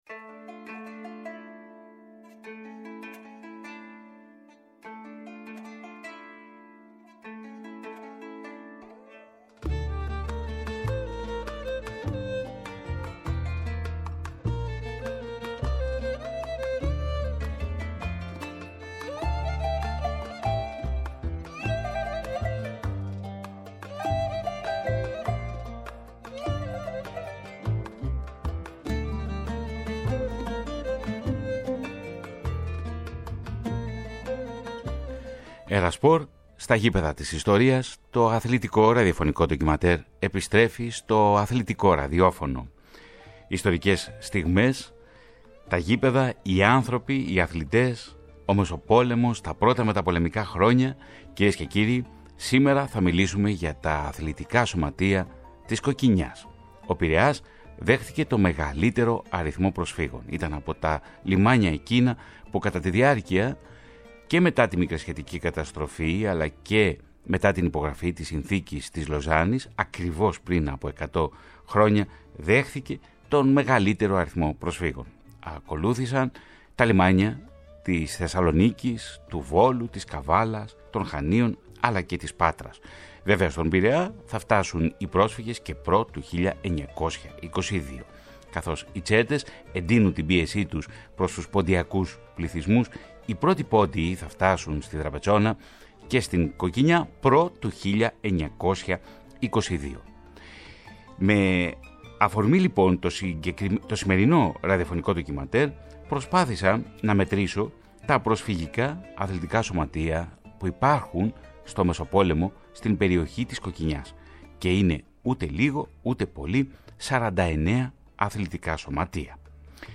ΝΤΟΚΙΜΑΝΤΕΡ